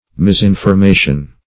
Search Result for " misinformation" : Wordnet 3.0 NOUN (1) 1. information that is incorrect ; The Collaborative International Dictionary of English v.0.48: Misinformation \Mis*in`for*ma"tion\, n. Untrue or incorrect information.